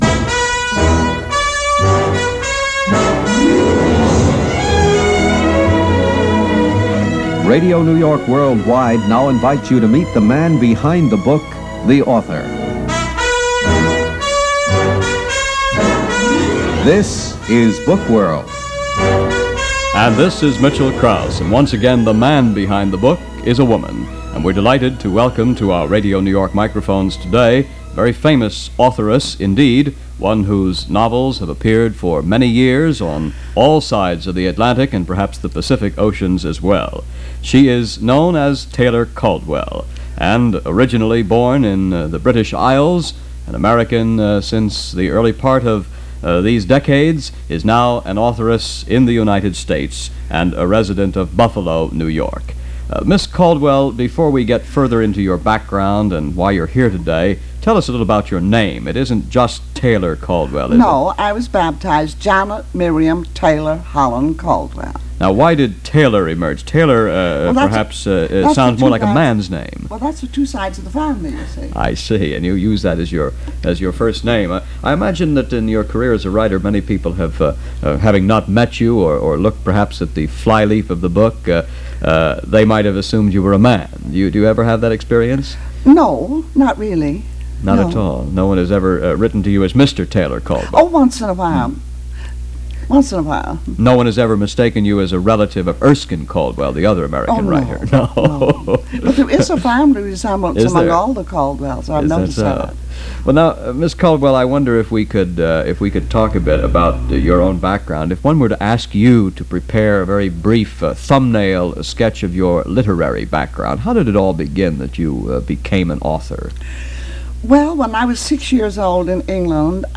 Taylor Caldwell Talks Books And Writing - 1963 - Past Daily "Talking About . . .." - recorded circa 1963 for Radio New York Worldwide.
Recorded in New York sometime in 1963.
Taylor-Caldwell-Interview.mp3